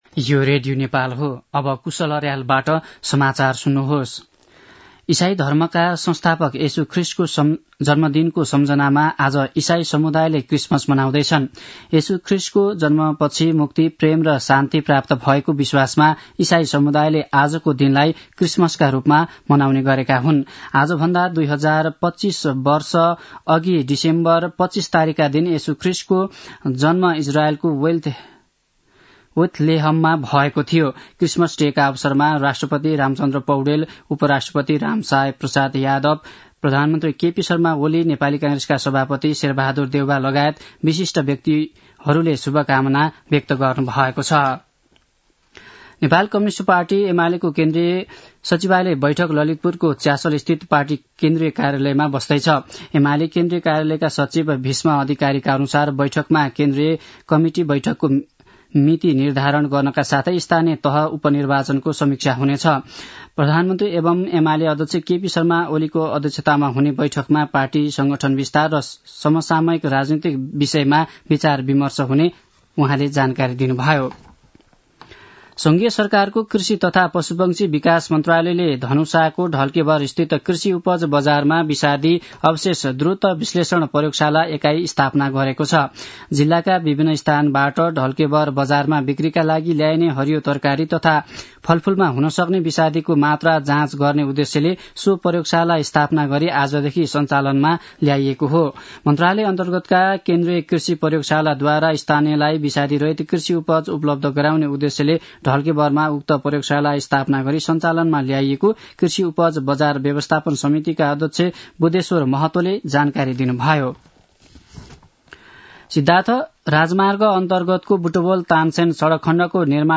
दिउँसो १ बजेको नेपाली समाचार : ११ पुष , २०८१
1-pm-nepali-news-1-19.mp3